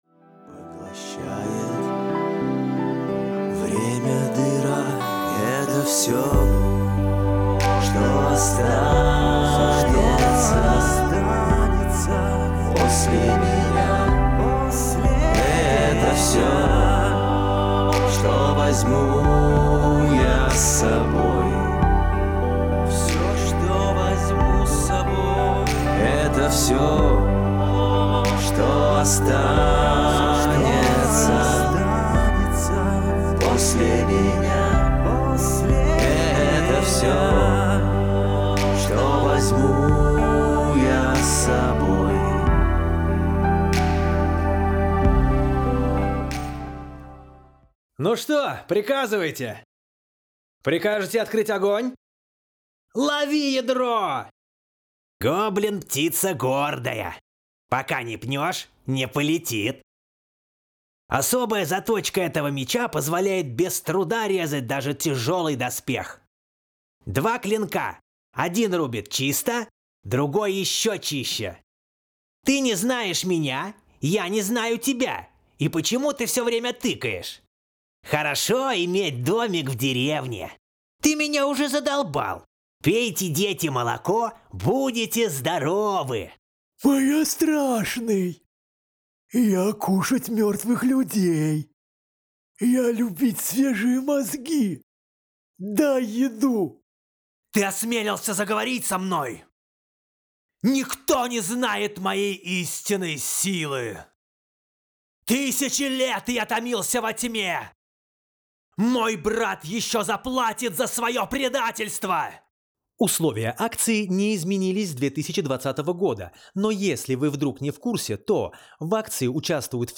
Скачать демо диктора
Записываю на студии, профессиональное оборудование, качественный звук.
Спокойный, уверенный баритон с позитивными интонациями подходит как для деловых, так и для развлекательных проектов.